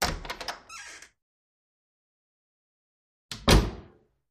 Creak
Door Open Close / Squeaks, Various; Wood Door Open Close 2